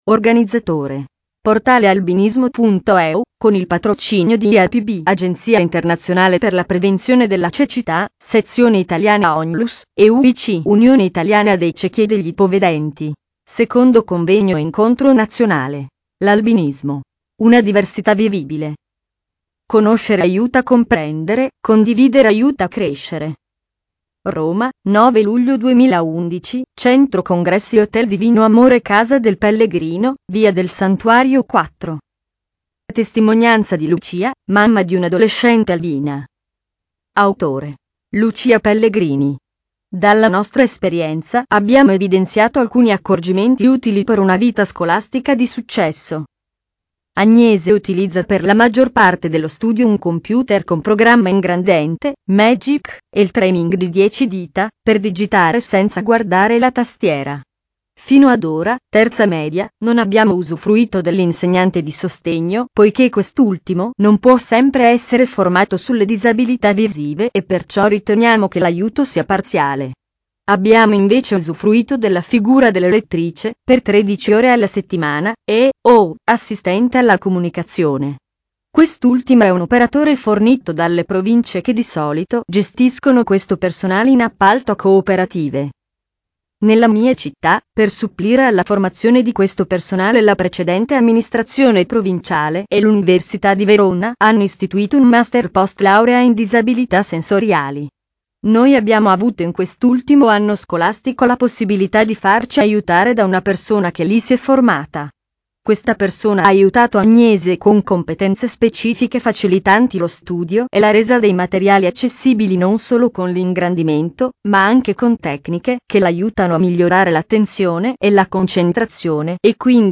2011 - L’Albinismo: una diversità vivibile - 2° Convegno Nazionale